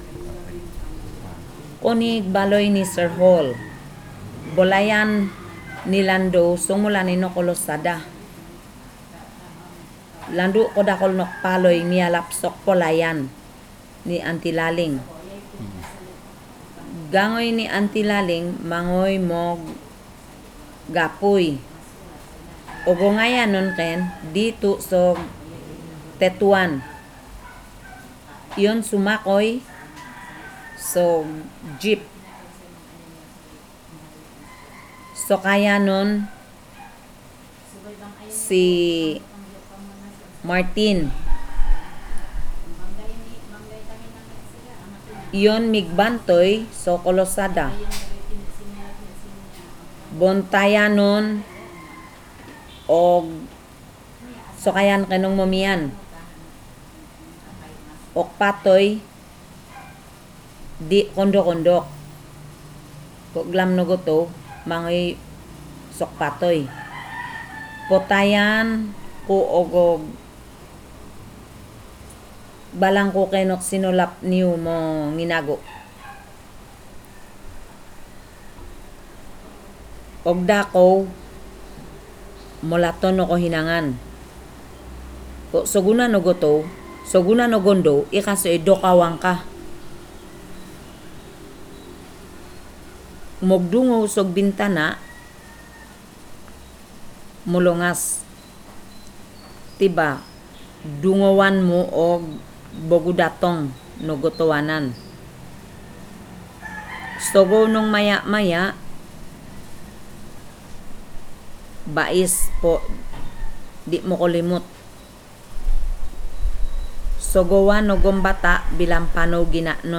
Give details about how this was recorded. wave audio file recorded on Zoom H4n Tetuan, Zamboanga City, Philippines